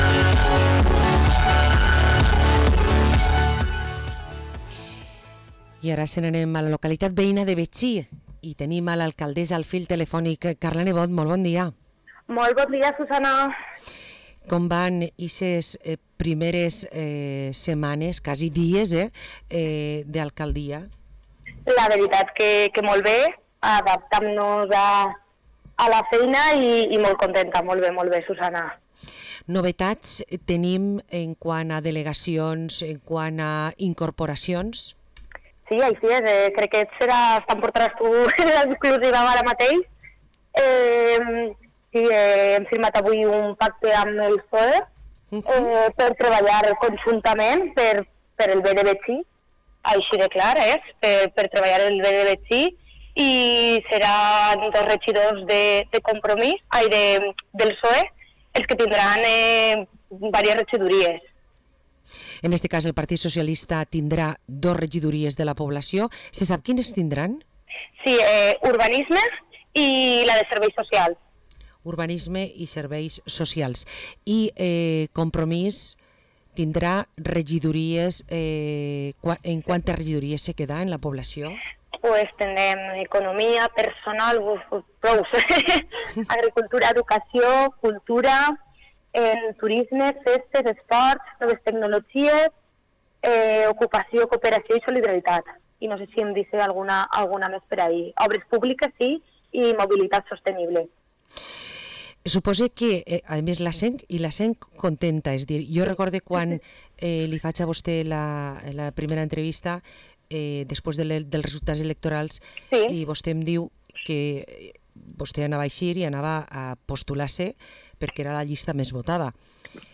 Parlem amb Carla Nebot, alcaldessa de Betxí